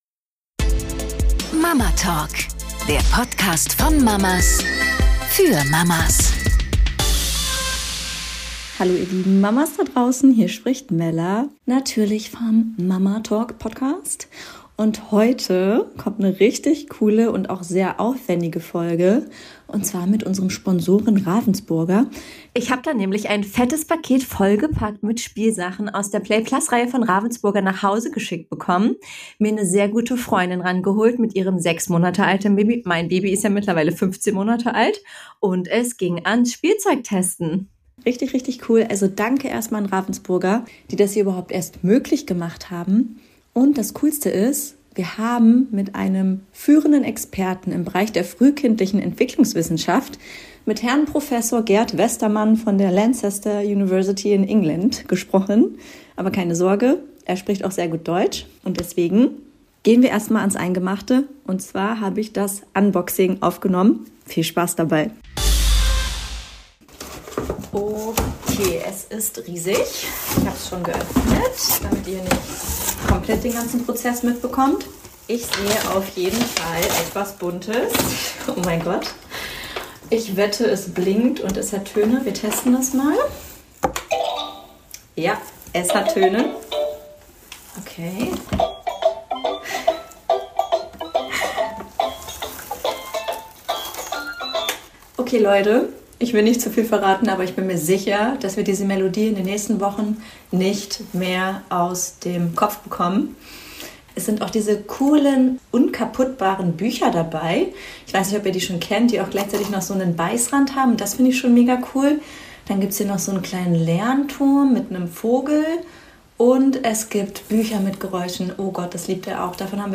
Los geht's also erstmal mit einem Unboxing.